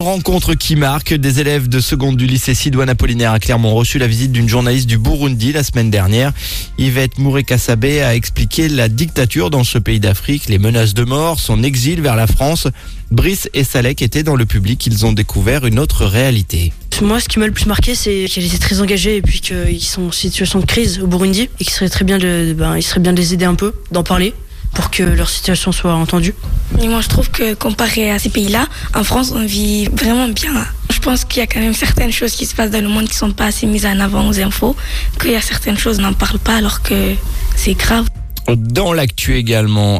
Ci-dessous un reportage radio sur NRJ dont vous trouverez deux extraits audio :